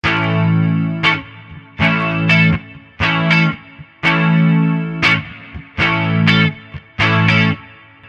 拍打吉他A大调
描述：清晰的A大调吉他开放和弦与合唱
Tag: 120 bpm Blues Loops Guitar Electric Loops 1.50 MB wav Key : Unknown